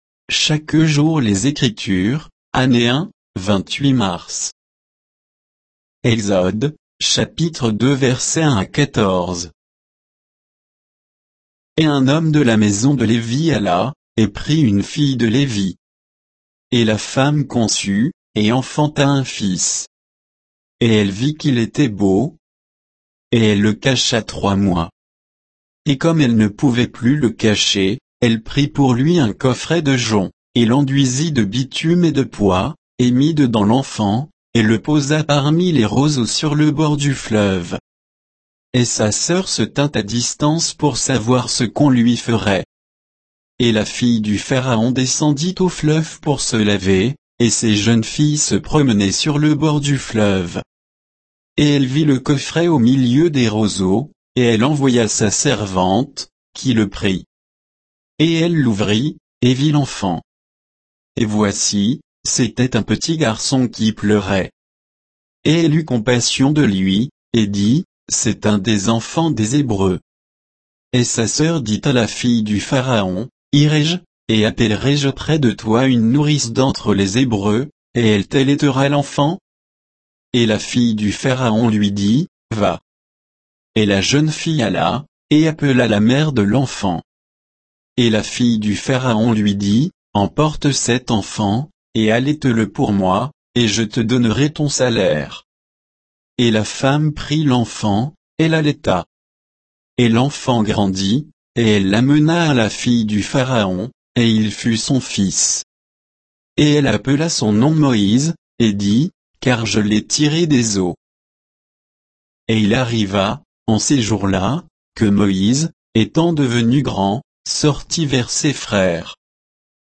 Méditation quoditienne de Chaque jour les Écritures sur Exode 2